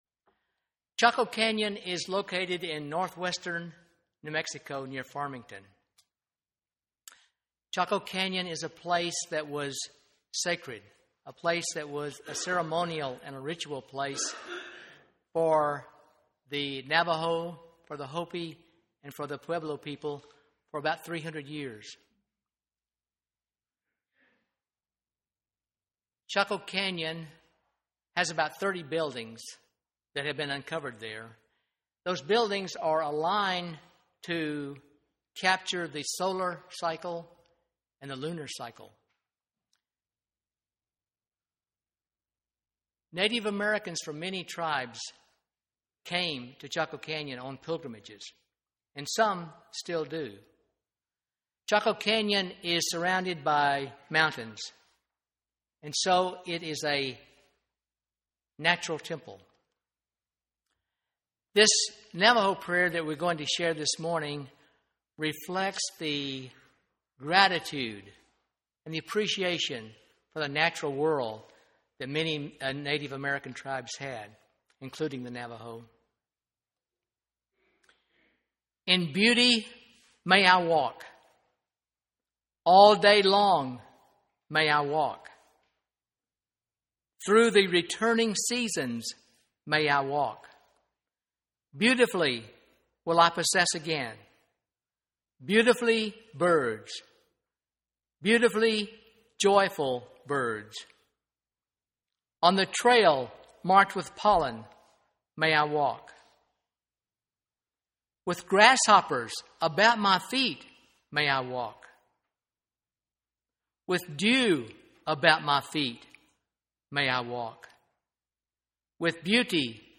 2007 The text of this sermon is unavailable but you can listen to the sermon by clicking the play button.